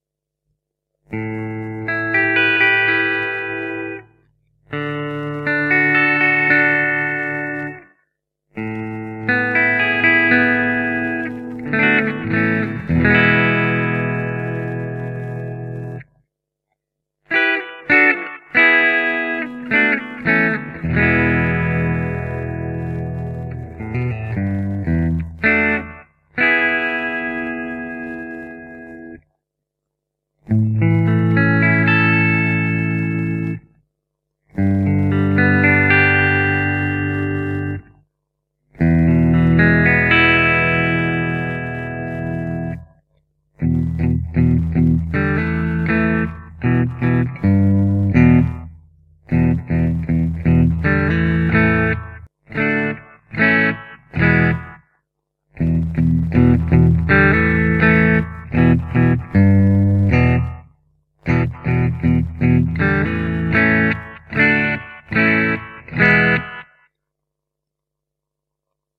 Dawn Angel alnico 3 humbucker, smooth, sweet tones
The Dawn Angel offers sweet, transparent cleans with unrivalled clarity.
The highs are warm, the lows are firm but not aggressive, the mids are slightly muted and perfectly balanced.
The relatively low output means that they aren't the best choice for driving a vintage amp into meltdown but turning the gain up a little on a modern amp lets the beautifully balanced tone shine through with as much dirt as you want.
Magnet Alnico 3